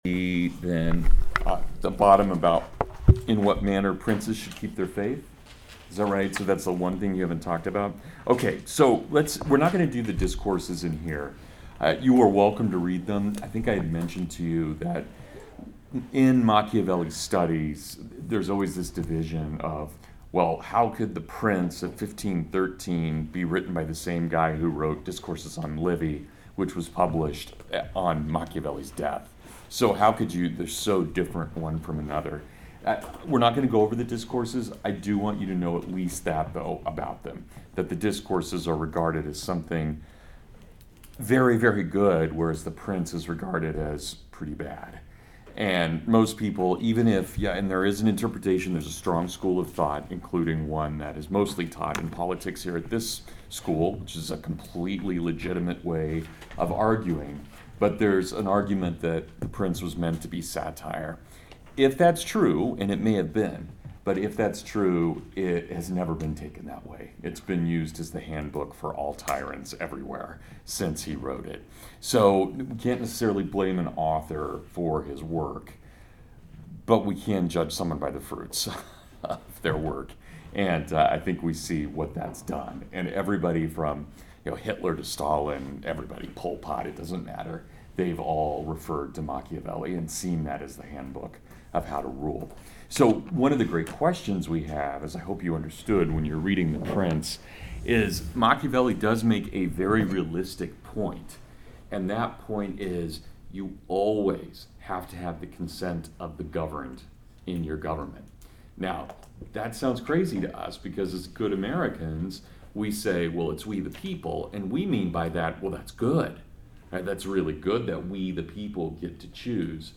Machiavelli vs. Socrates (Full Lecture)